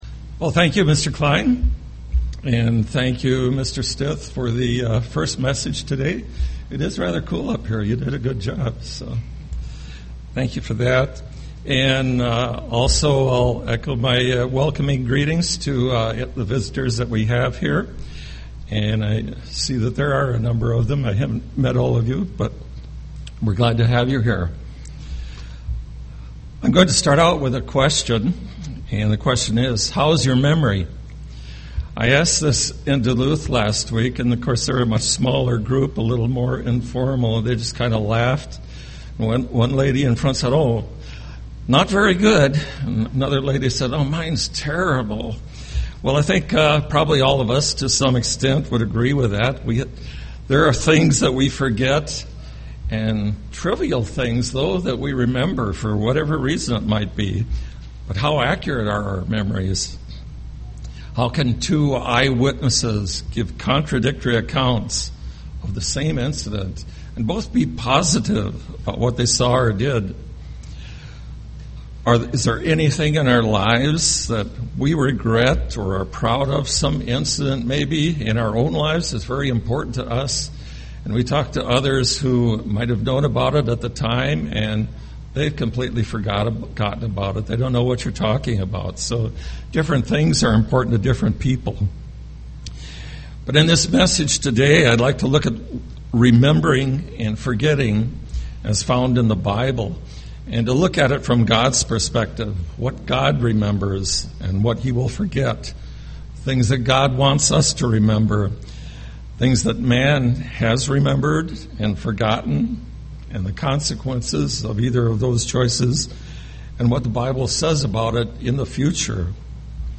UCG Sermon remember rememberance forgetting forget Studying the bible?